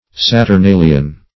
Search Result for " saturnalian" : The Collaborative International Dictionary of English v.0.48: Saturnalian \Sat`ur*na"li*an\, a. 1.